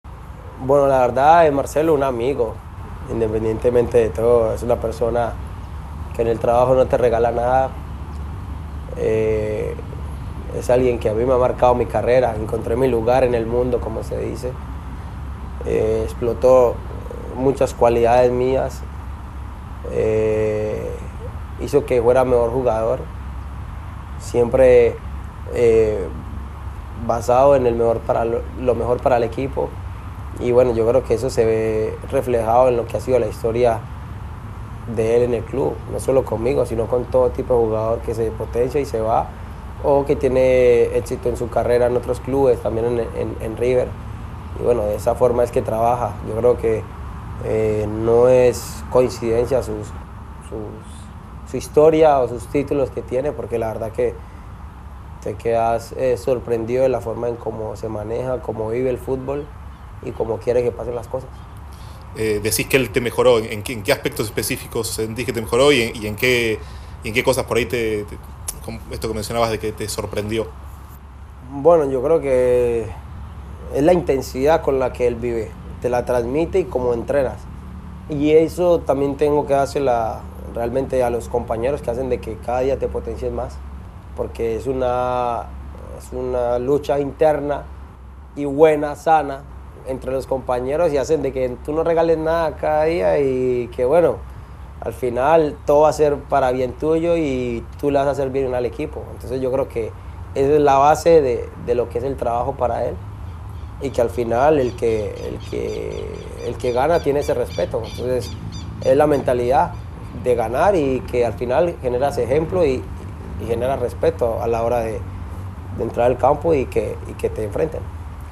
(Juan Fernando Quintero en entrevista con la Conmebol)